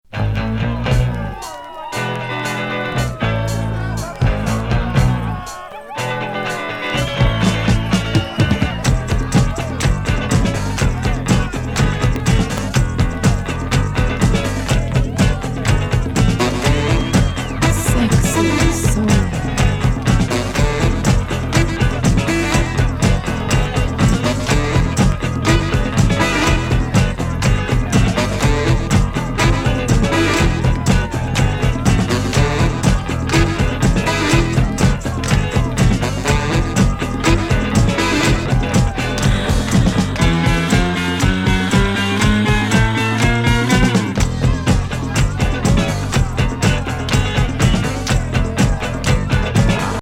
セクシーボイス入りファンキー・ディスコ！
なホーン隊がナイス！